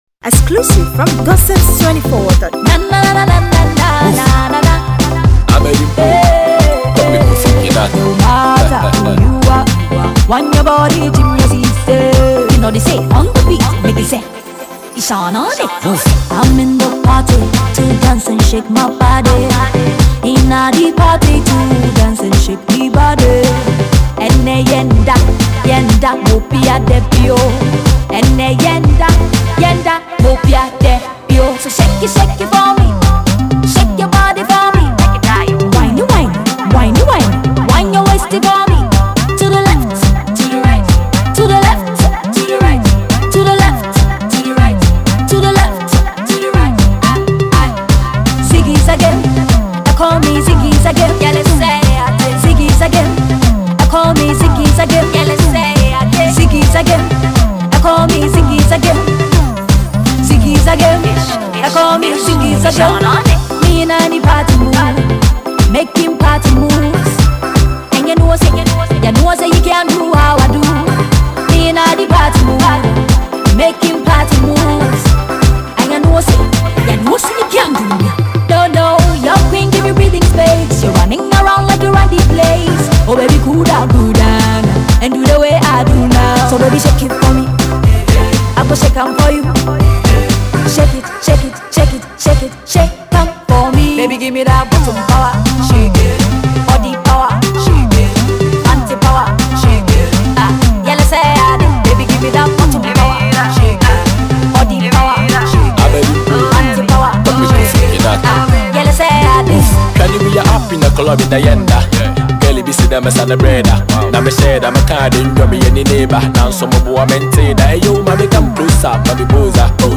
female vocalist
club banger